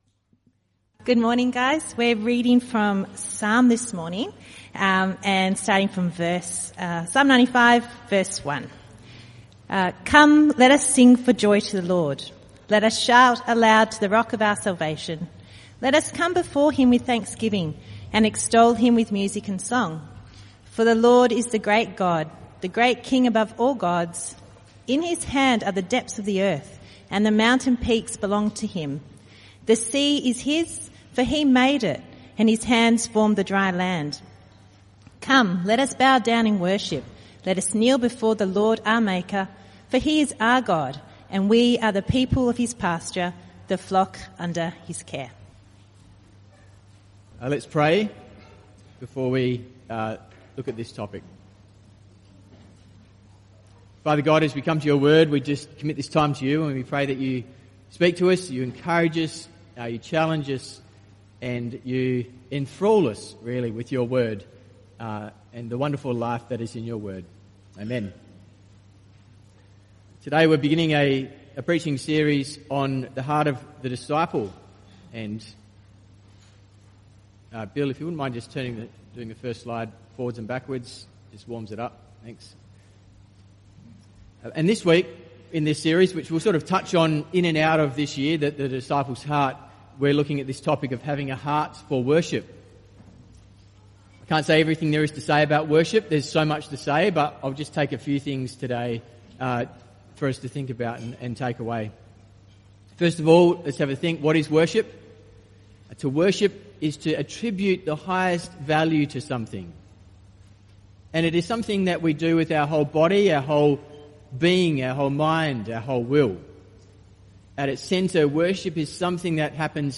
Type: Sermons